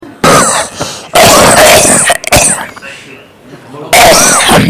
有痰咳.mp3